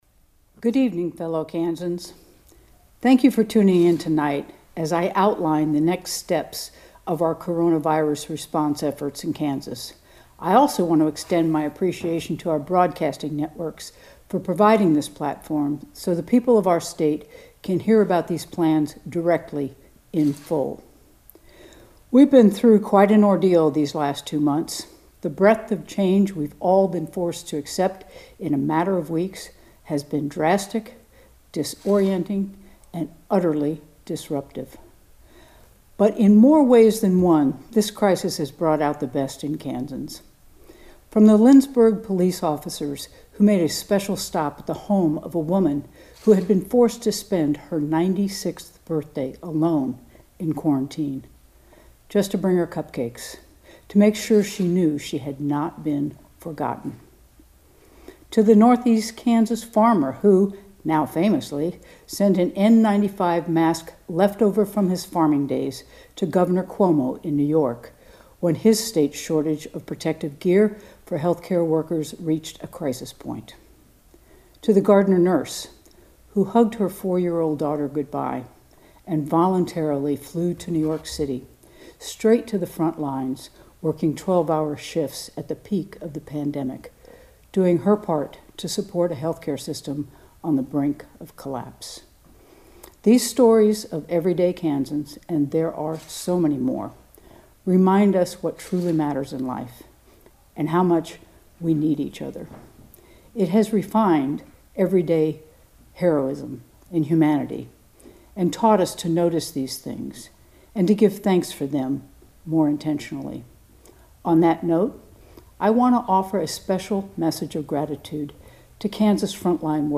Here is Gov. Kelly’s full address uninterrupted as aired on KMAN.